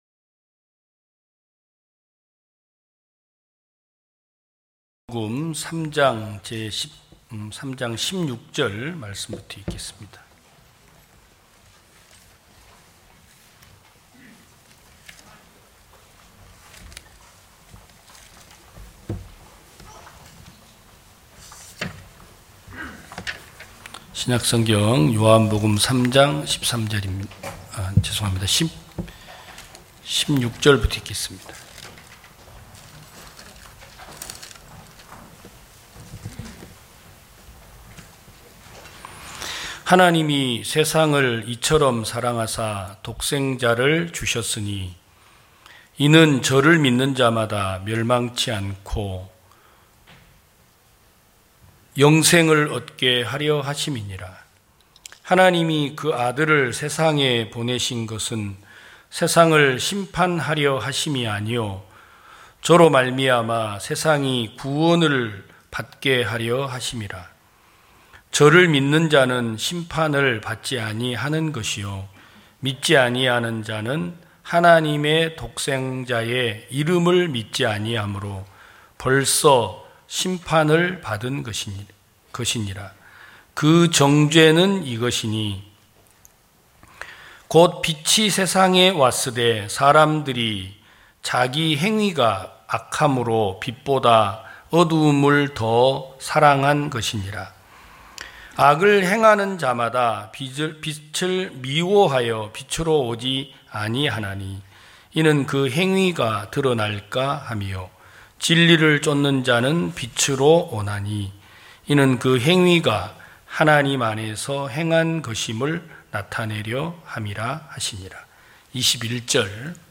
2022년 06월 19일 기쁜소식부산대연교회 주일오전예배
성도들이 모두 교회에 모여 말씀을 듣는 주일 예배의 설교는, 한 주간 우리 마음을 채웠던 생각을 내려두고 하나님의 말씀으로 가득 채우는 시간입니다.